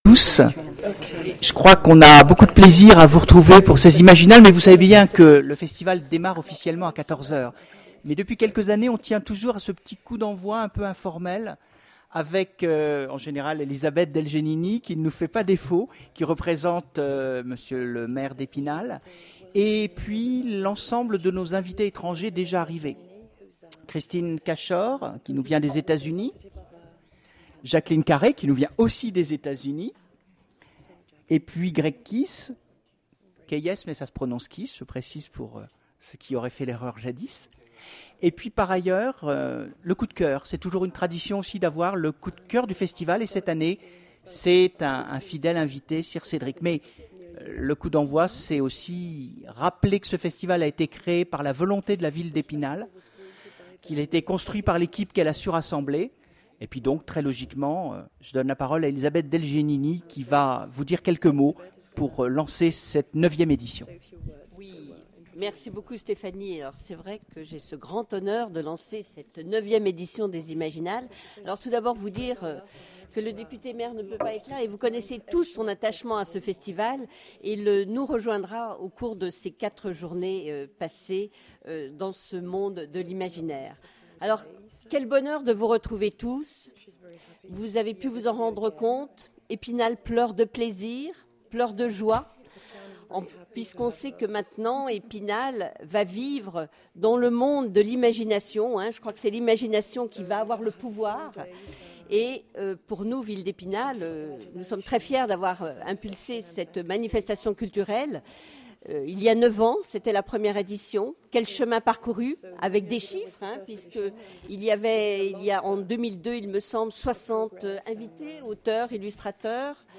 Voici l'enregistrement de la conférence d'ouverture des Imaginales 2010